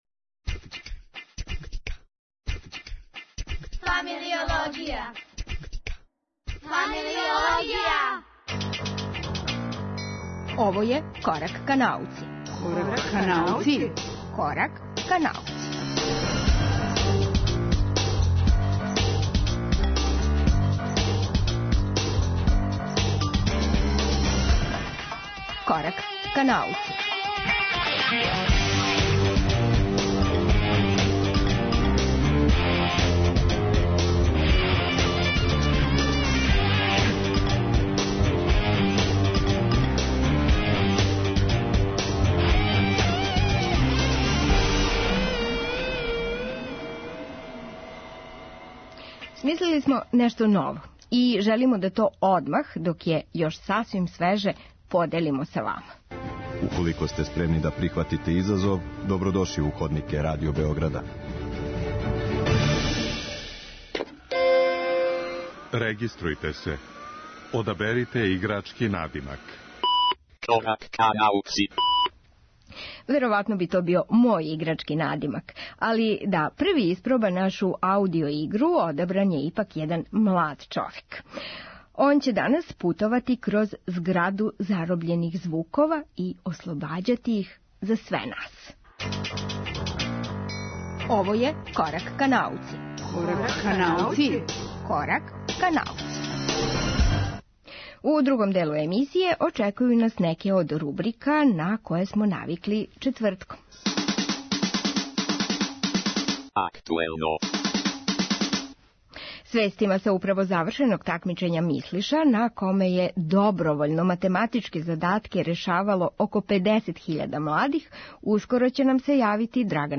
Звучи као видео игра, а заправо је аудио игра.